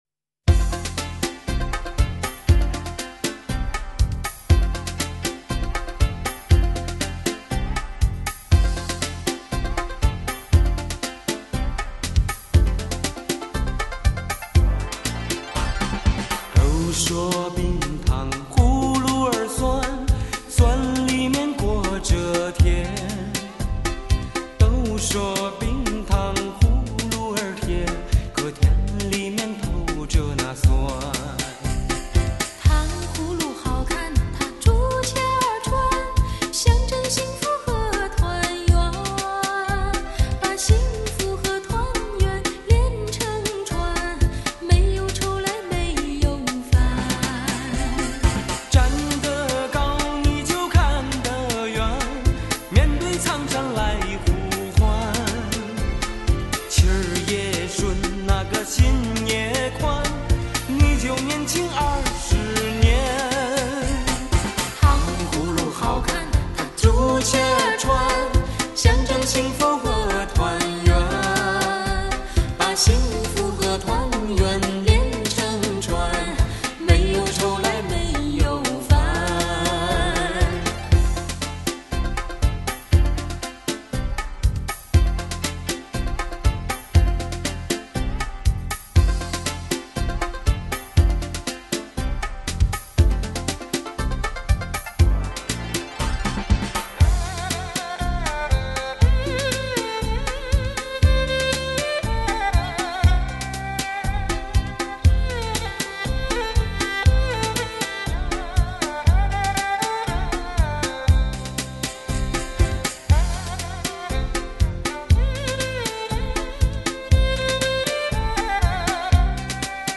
谨把此系列送给喜爱舞蹈音乐的朋友